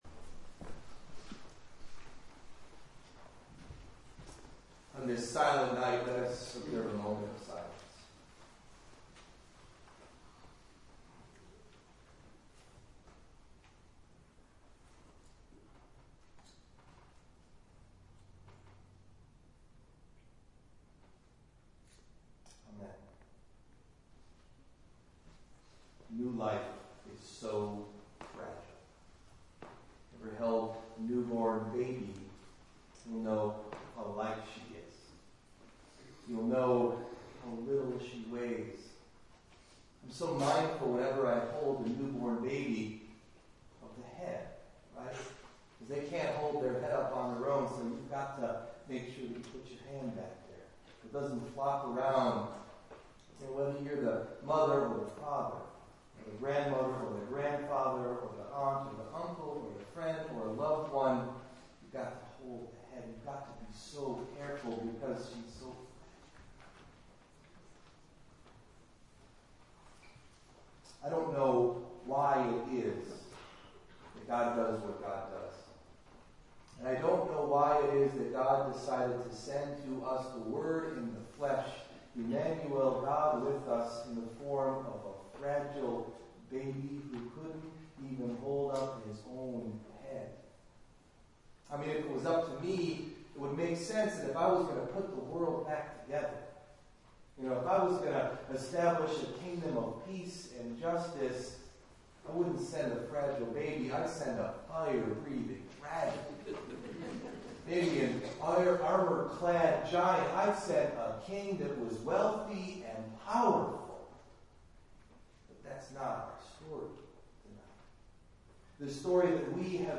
Christmas Eve Meditation: “Fragile”
Delivered at: The United Church of Underhill